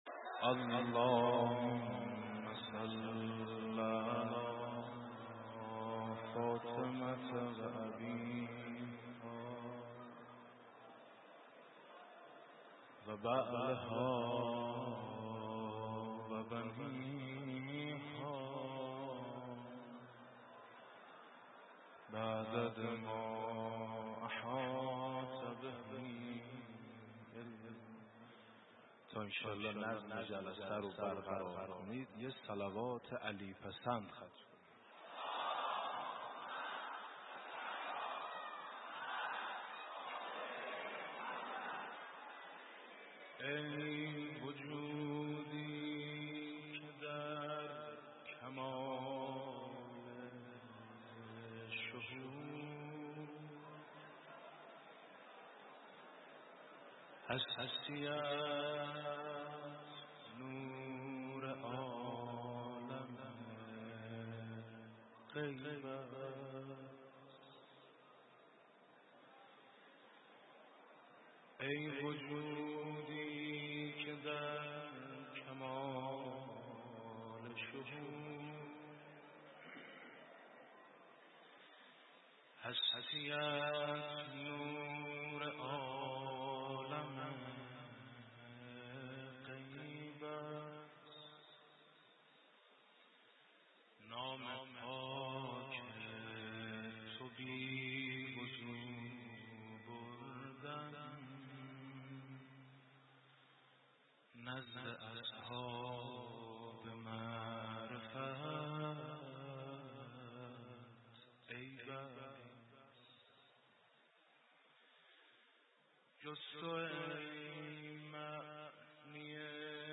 مداحی حاج میثم مطیعی در حسینیه امام خمینی(ره)
مداحی حاج میثم مطیعی چهارمین شب عزاداری فاطمیه در حسینیه امام خمینی